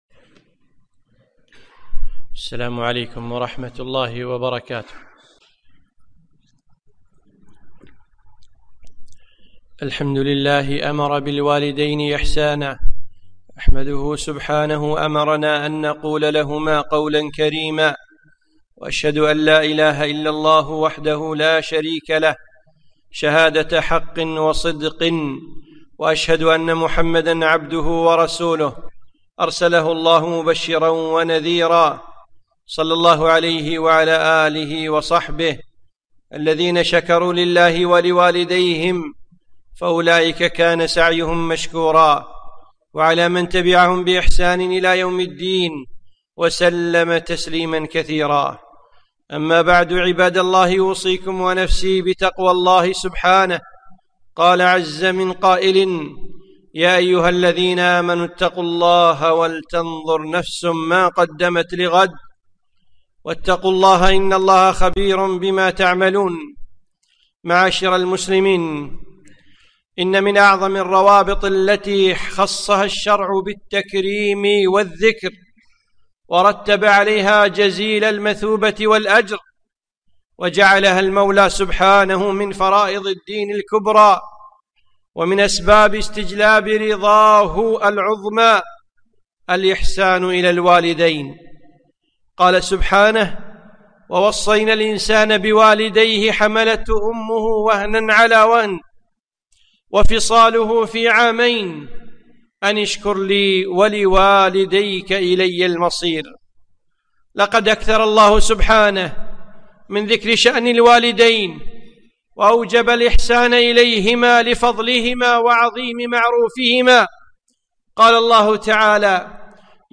خطبة - وبالوالدين إحساناً